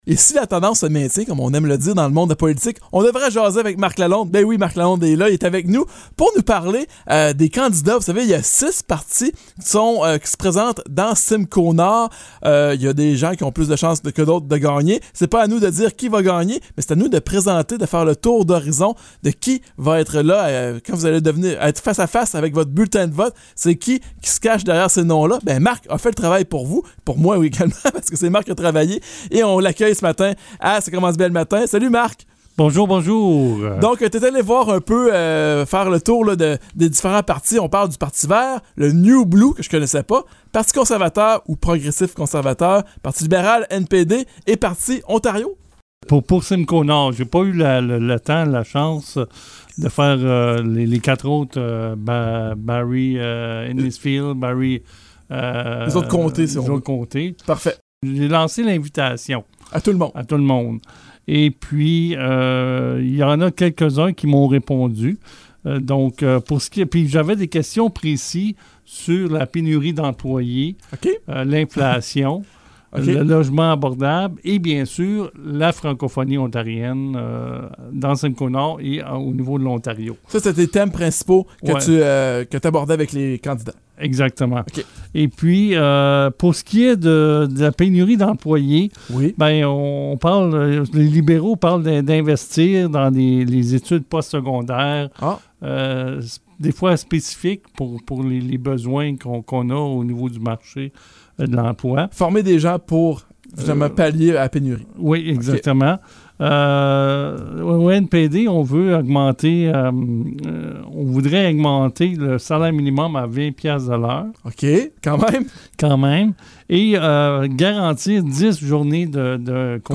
À un peu plus d'une semaine de la date du vote du 2 juin, l'émission matinale de CFRH, Ça commence bien le matin, a fait un survol des réponses obtenu des représentants des partis dans la course aux élections ontariennes.
Ils ont été questionnés sur quatre grands thèmes : la pénurie d'employés, l'inflation, le logement abordable et la situation du français en Ontario. CFRH a reçu à son antenne, des représentants du Parti libéral, du NPD, du Parti Ontario et du Parti vert.